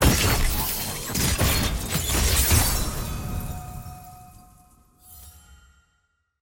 sfx-tier-wings-promotion-to-platinum.ogg